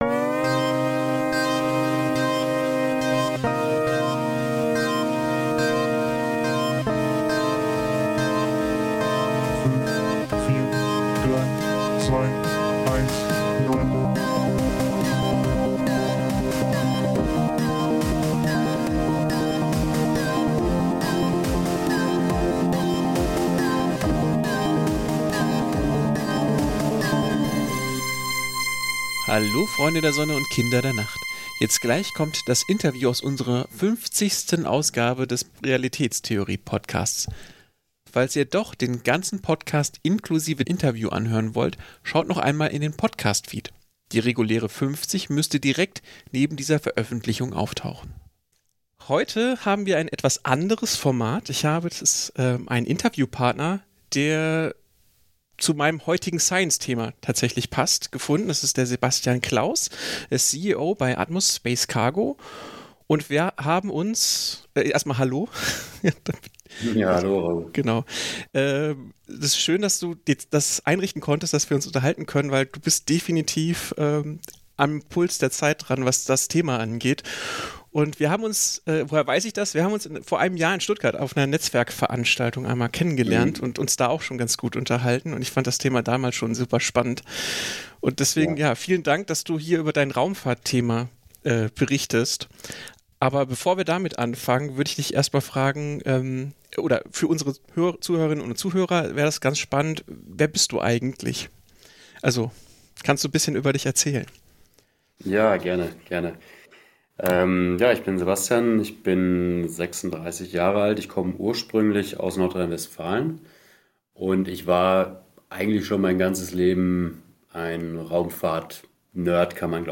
RT050_interview_standalone.mp3